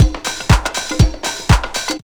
C1HOUSE120.wav